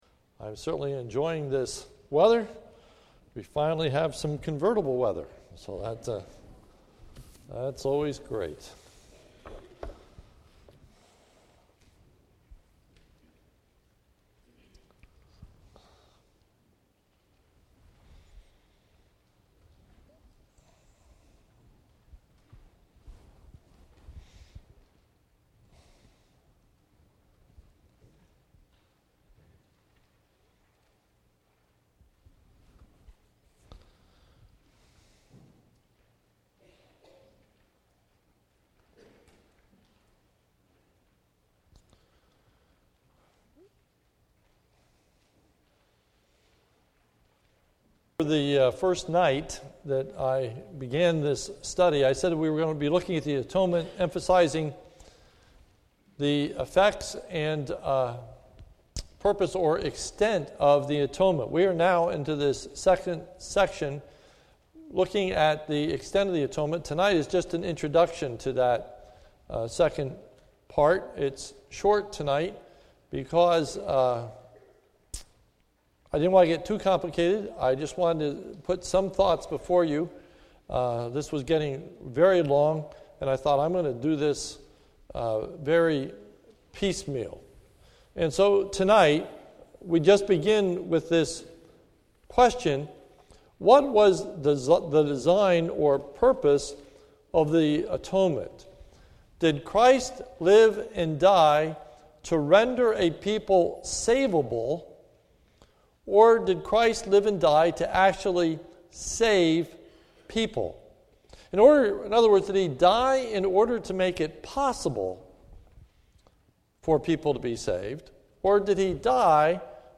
This is a sermon recorded at the Lebanon Bible Fellowship Church